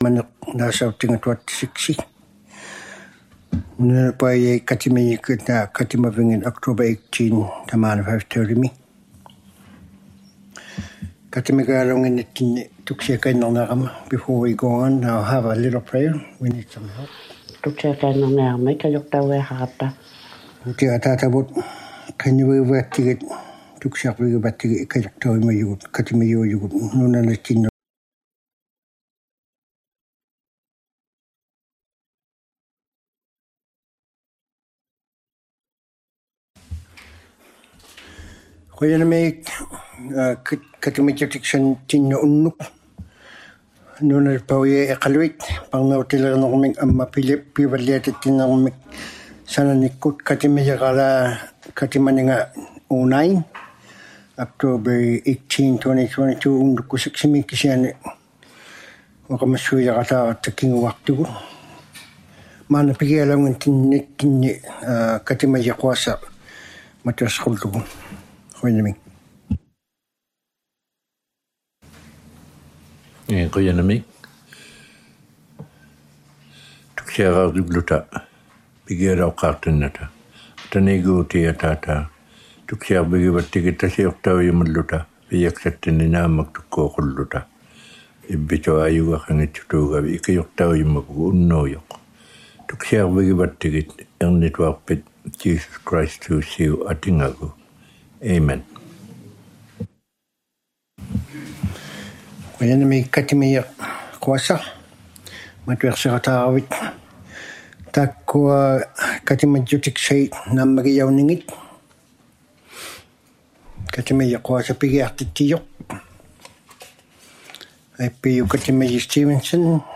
ᐸᕐᓇᐅᑎᓕᕆᓂᕐᒥᑦ ᐊᒻᒪ ᐱᕙᓪᓕᐊᑎᑦᑎᓂᕐᒥᑦ ᓴᓇᓂᒃᑯᑦ ᑲᑎᒪᔨᕋᓛᑦ ᑲᑎᒪᓂᖓᑦ #09 Planning and Development Committee of the Whole Meeting #09 | City of Iqaluit